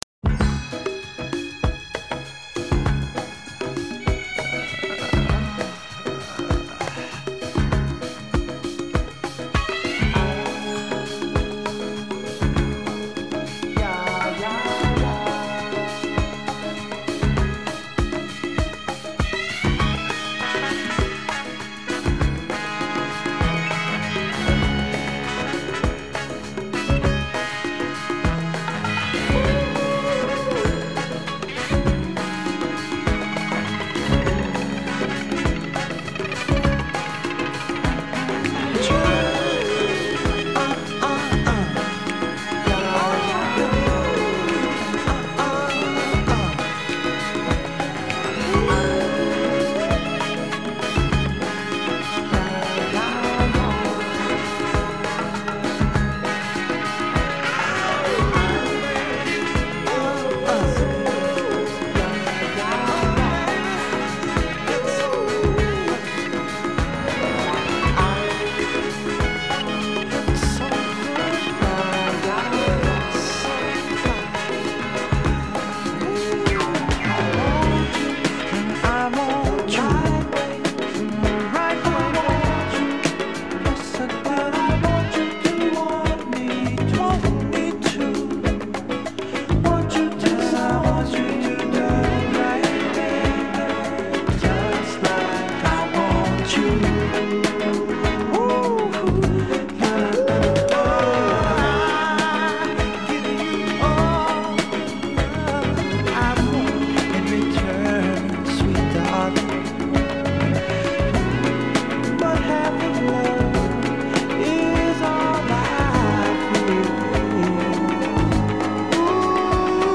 Una irresistibile sensazione di ritmo e divertimento